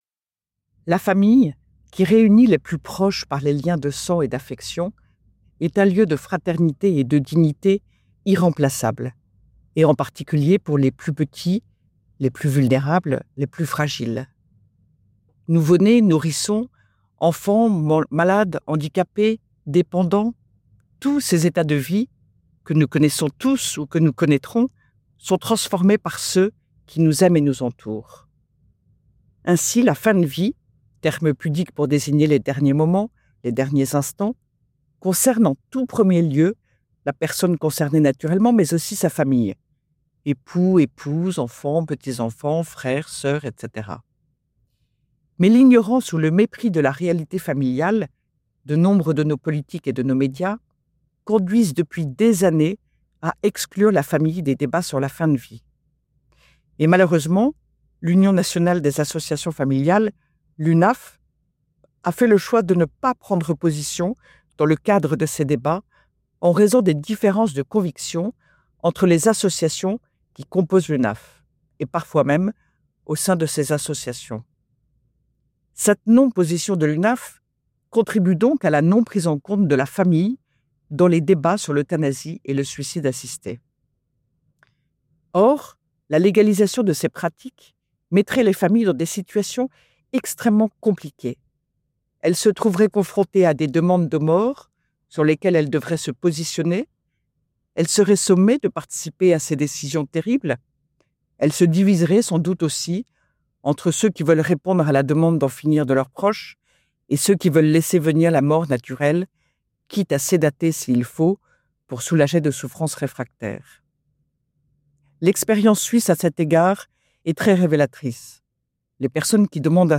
radio-esperance-fin-vie-famille-1.mp3